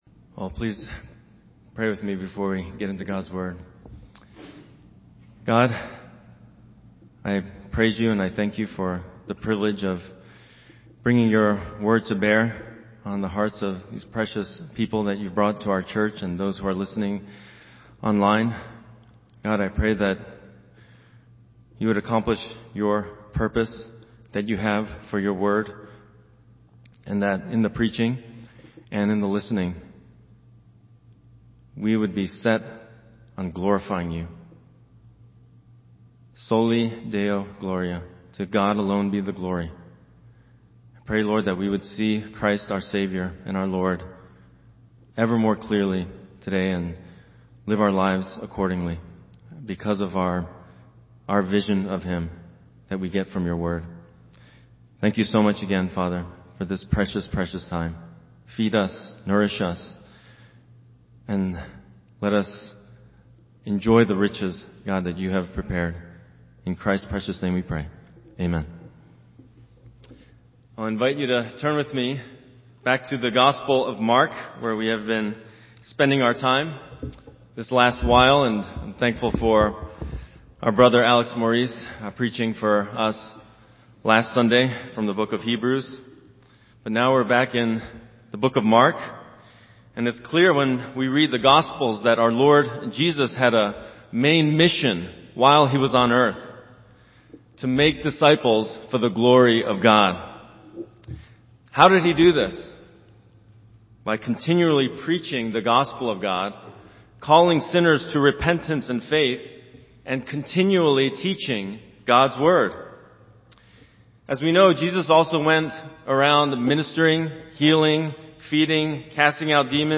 Sermon Theme/Outline: Jesus demonstrates that He has the authority to forgive man’s sins by His powerful healing of a paralytic